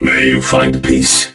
robo_bo_kill_01.ogg